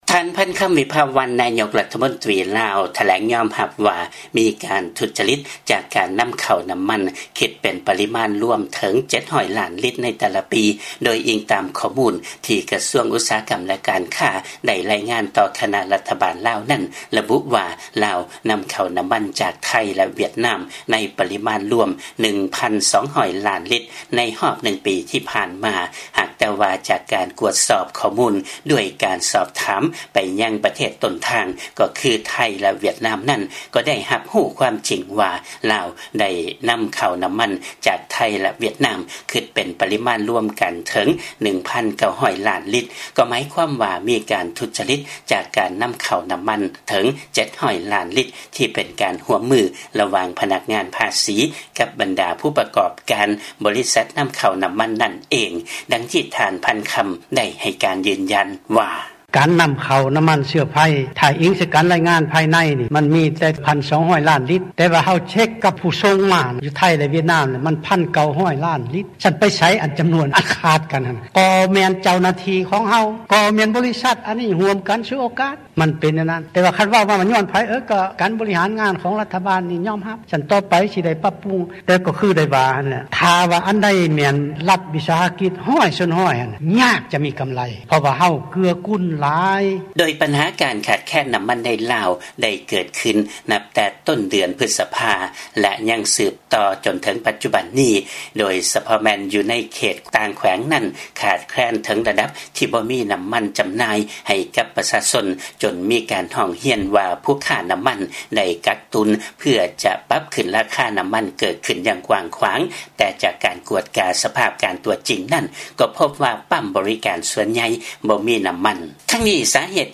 ເຊີນຟັງລາຍງານ ນາຍົກລາວ ຍອມຮັບວ່າ ມີການທຸດຈະລິດຈາກການນຳເຂົ້ານ້ຳມັນຄິດເປັນປະລິມານຮວມເຖິງ 700 ລ້ານລິດ ໃນແຕ່ລະປີ